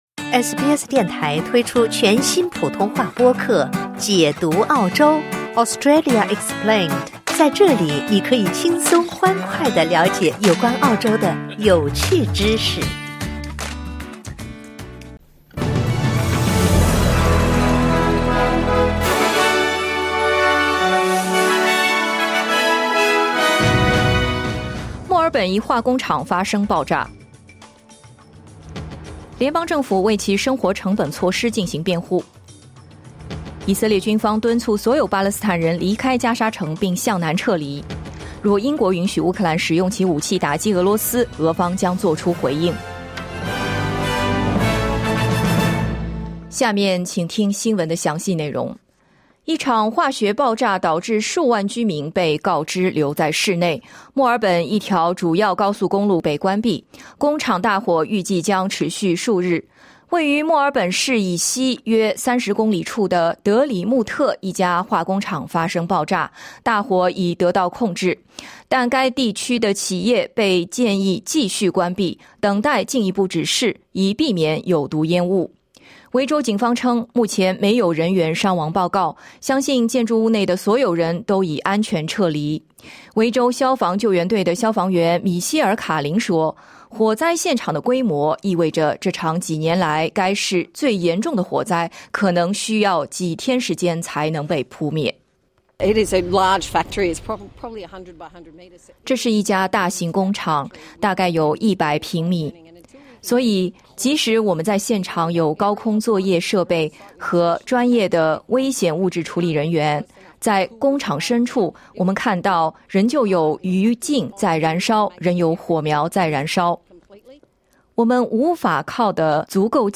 SBS早新闻 (2024年7月11日)